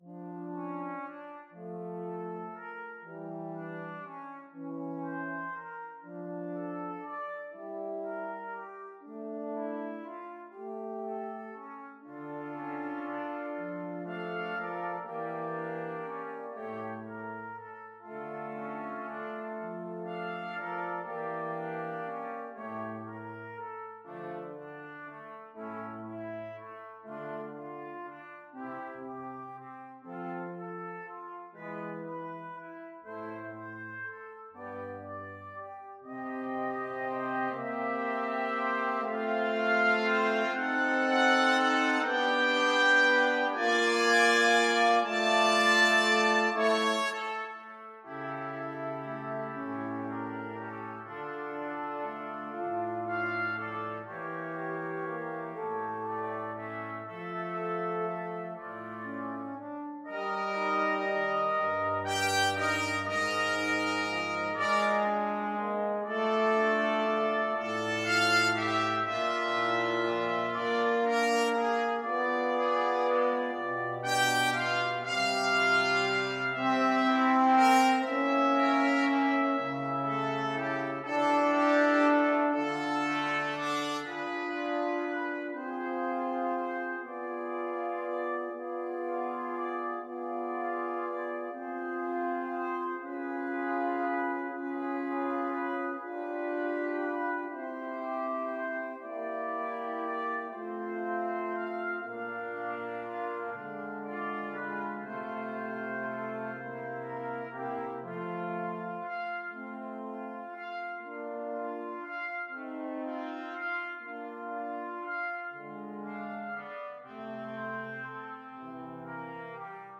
Trumpet 1Trumpet 2Trumpet 3French HornEuphonium
12/8 (View more 12/8 Music)
= 120 Larghetto
Classical (View more Classical Brass Quintet Music)